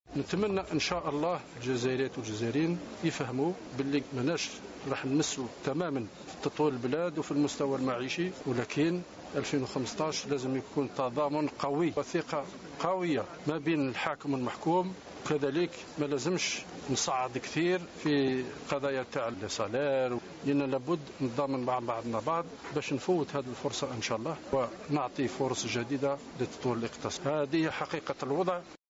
سلال متحدثا عن أهم القرارات المنبثقة عن الاجتماع المصغر الذي عقده رئيس الجمهورية